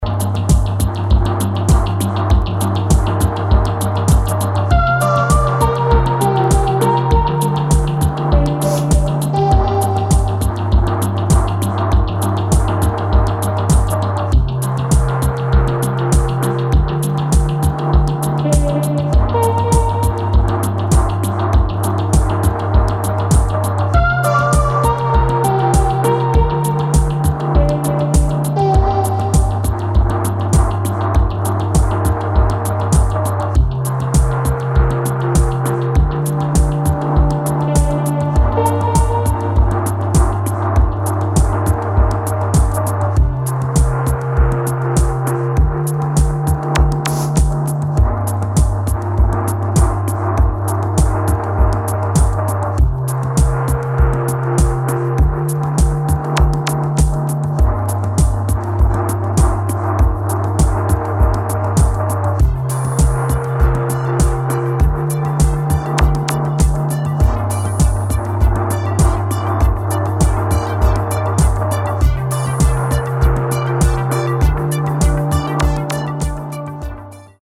[ DEEP HOUSE ]